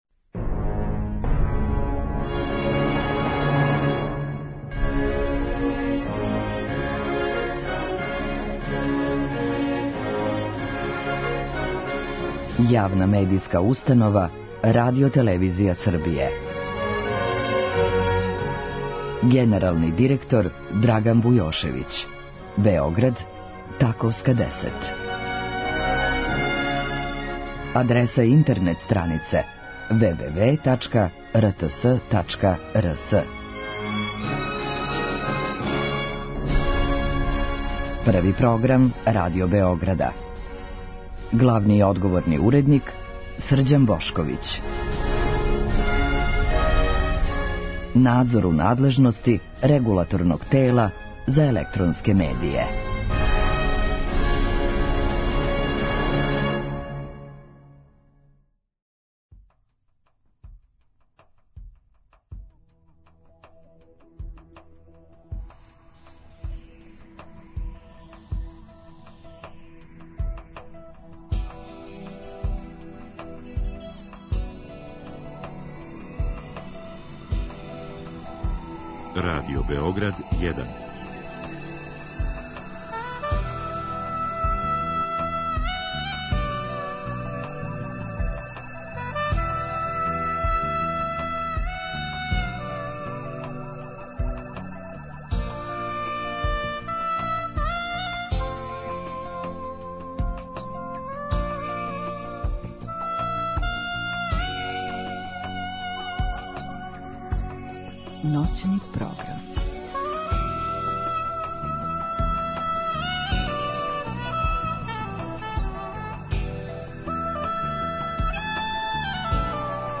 У другом сату гошћи постављамо питања слушалаца која су стигла путем наше Инстаграм странице.